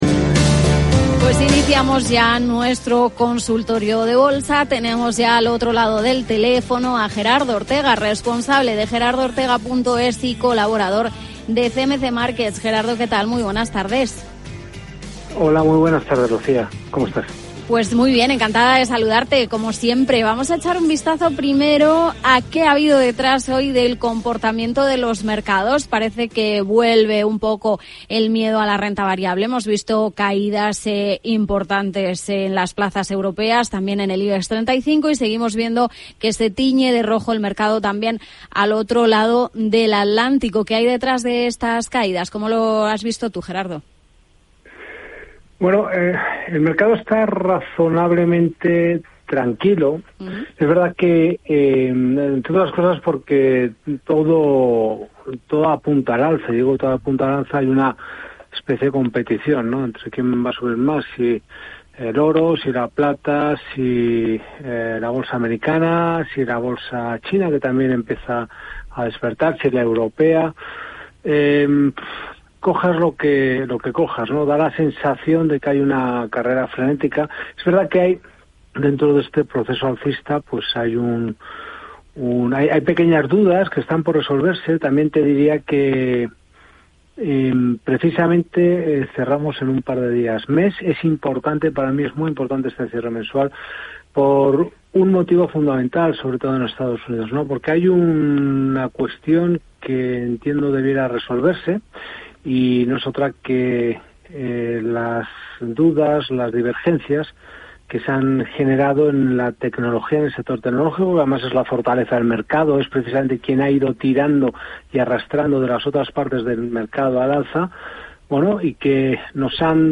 AUDIO CONSULTORIO BURSÁTIL EN CAPITAL RADIO